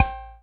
Index of /90_sSampleCDs/Classic_Chicago_House/Drum kits/kit01
cch_09_perc_clave_high_ping_wire.wav